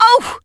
piper_hurt_05.wav